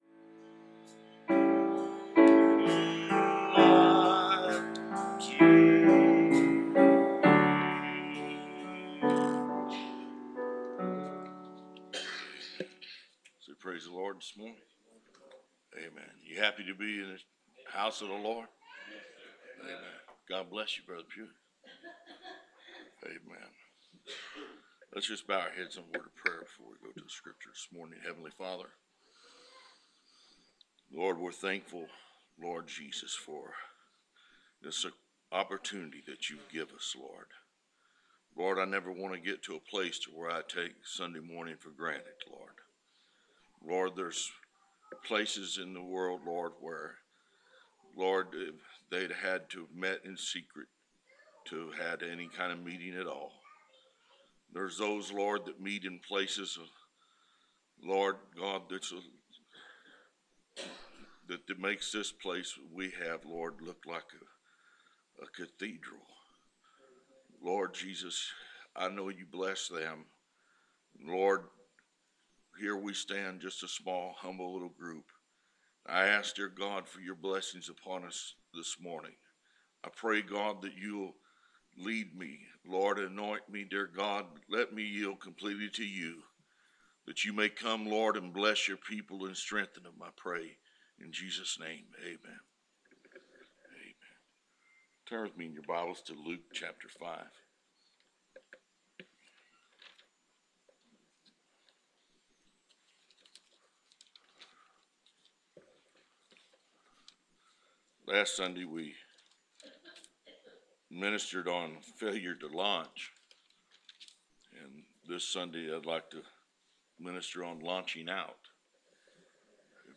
Preached February 7, 2016